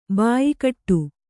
♪ bāyi kaṭṭu